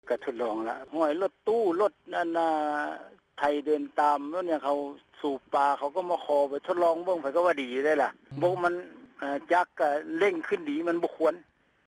ຊາວກະສິກອນ ທ່ານນຶ່ງ ທີ່ໄດ້ເຂົ້າຮ່ວມ ໂຄງການດັ່ງກ່າວ ເວົ້າວ່າ: